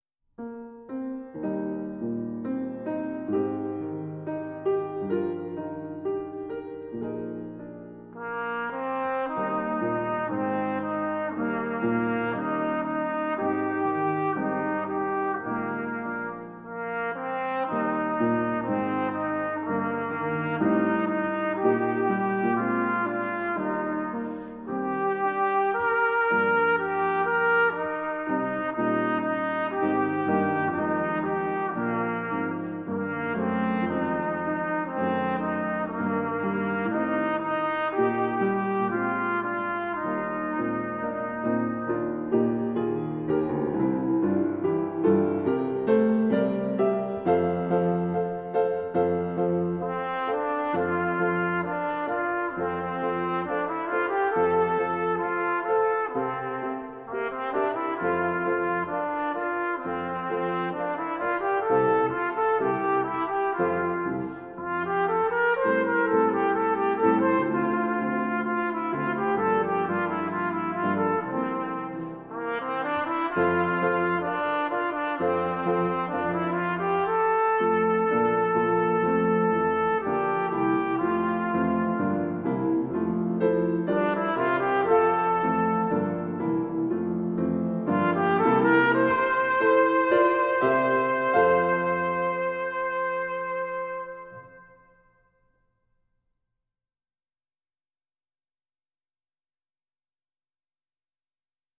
SOLO BRASS
Demonstration Recording